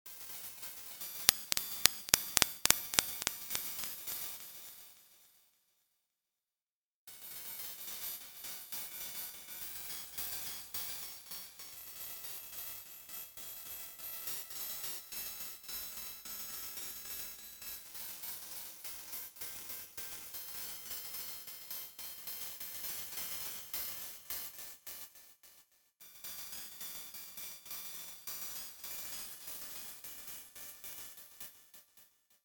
ラップ音を含むホラーBGM
フリーBGM ラップ音のあるホラーBGM